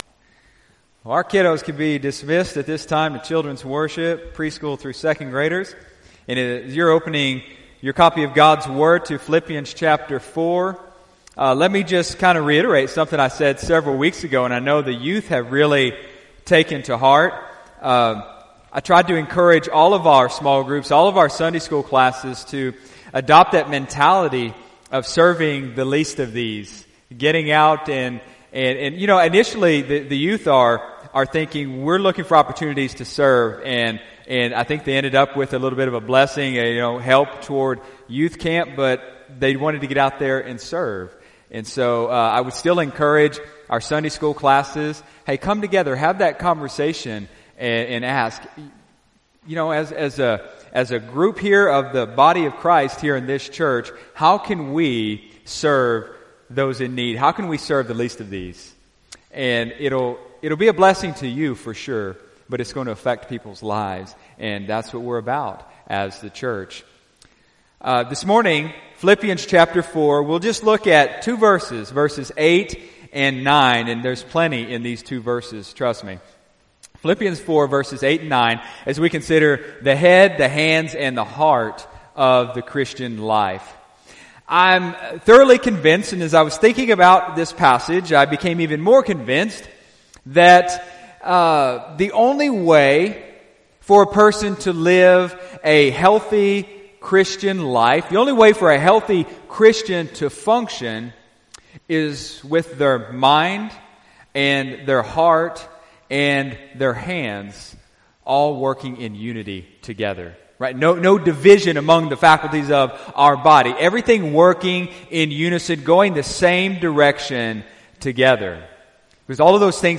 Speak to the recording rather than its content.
Sunday, October 14, 2018 (Sunday Morning Service)